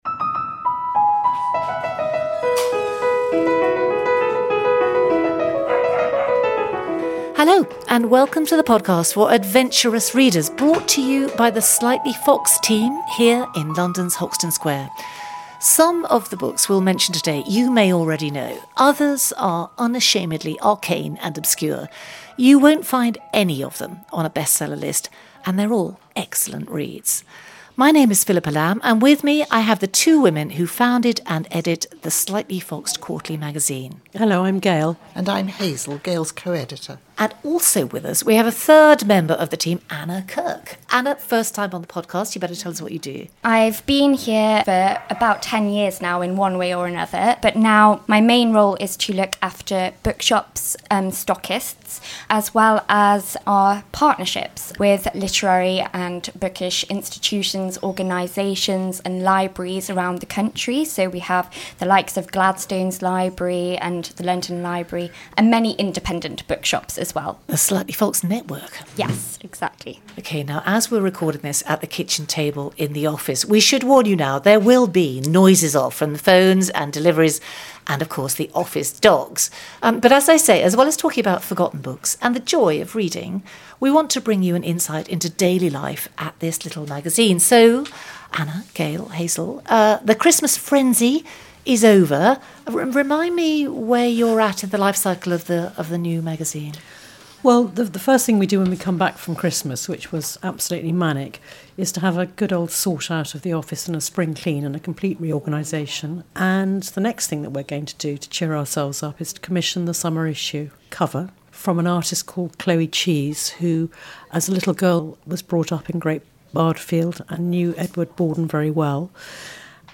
Tawny owl hoot sound effects